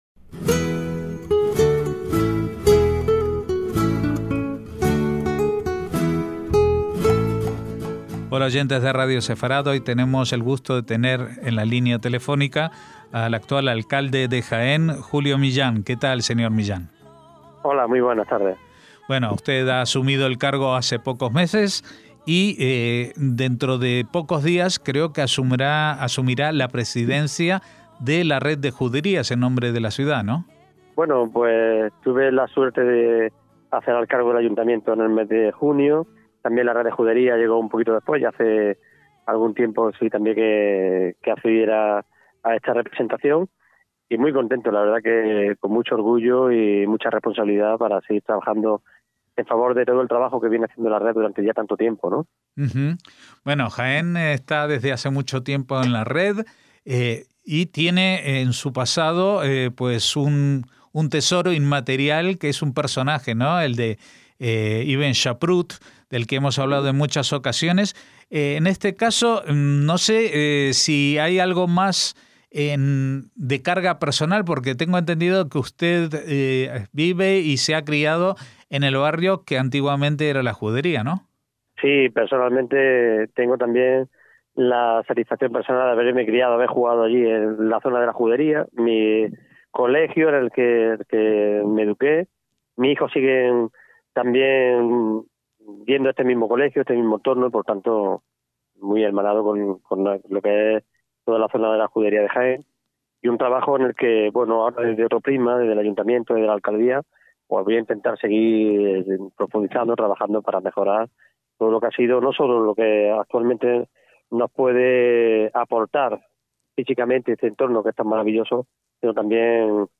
JUDERÍAS EN RED - El pasado 12 de diciembre tuvo lugar la 53ª Asamblea General Ordinaria de la Red de Juderías de España, que se celebró en la ciudad andaluza de Jaén, que ostenta la presidencia. Hablamos de ello con su nuevo alcalde, Julio Millán, que se crió en las mismas calles en que hace siglos moraban los judíos, quien, además, compartió con nosotros los nuevos planes de la Red, encaminados a potenciar una mayor promoción en el mercado nacional, además de proyectos enfocados en el judeoespañol, la gastronomía sefardí y la formación online de guías turísticos.